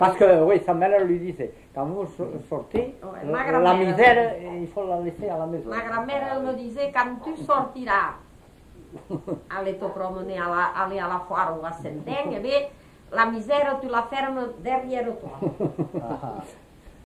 Genre : forme brève
Type de voix : voix de femme
Production du son : récité
Classification : locution populaire